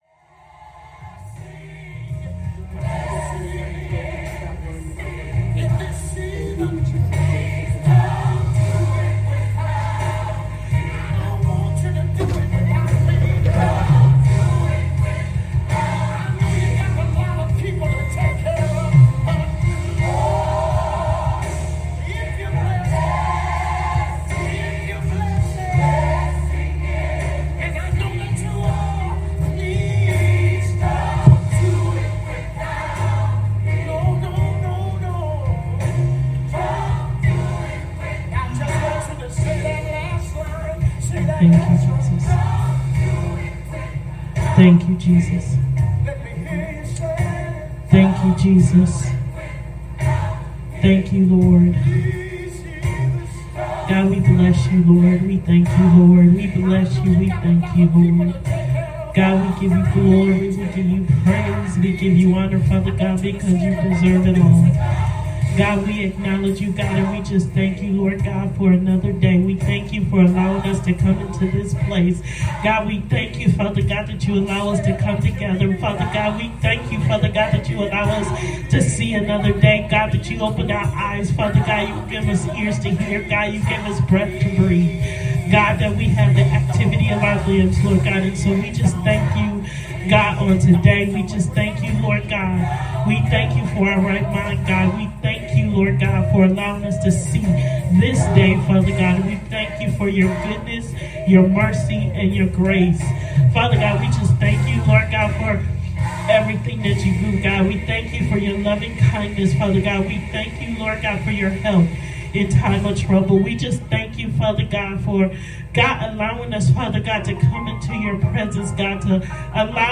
Saturday Afternoon Prayer